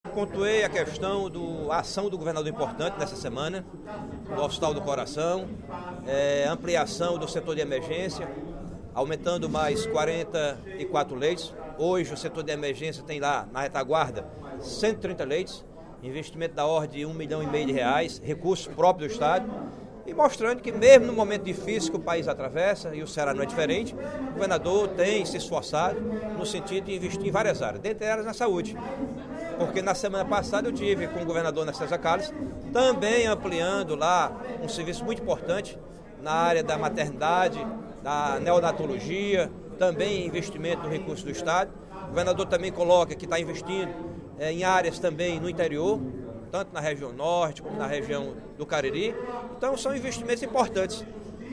O deputado Antônio Granja (PDT) comemorou, nesta quinta-feira (01/06), durante o primeiro expediente da sessão plenária, investimentos realizados na saúde por parte do Governo do Estado.